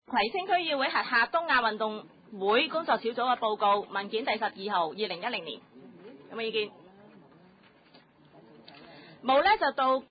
葵青區議會第六十三次會議